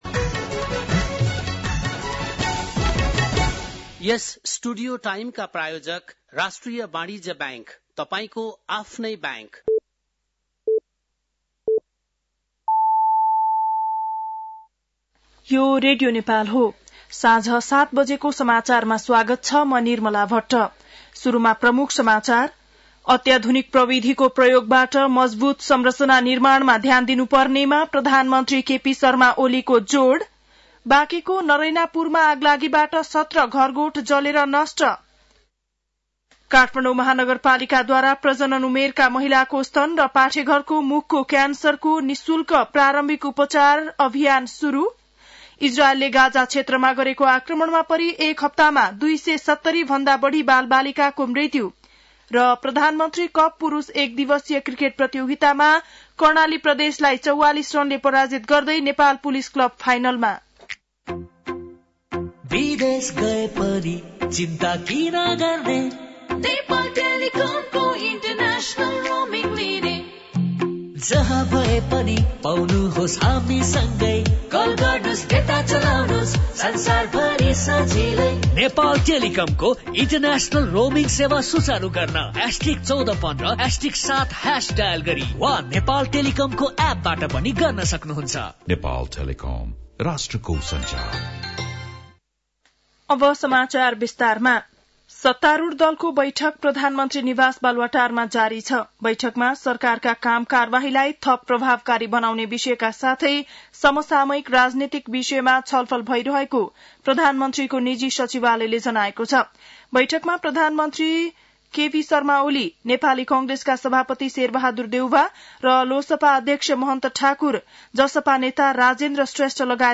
बेलुकी ७ बजेको नेपाली समाचार : १२ चैत , २०८१
7-PM-Nepali-NEWS-12-12.mp3